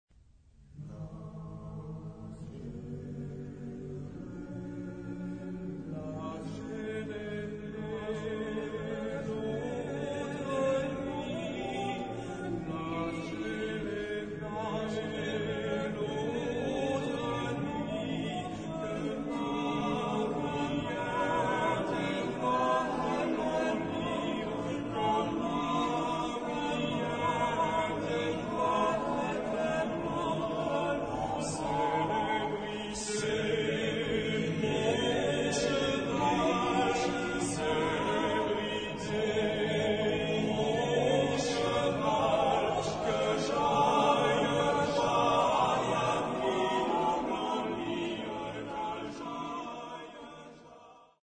Genre-Style-Forme : Profane ; Populaire
Caractère de la pièce : douloureux
Type de choeur : TTBB  (4 voix égales d'hommes )
Solistes : Ténor (1) / Baryton (1)  (2 soliste(s))
Origine : Bretagne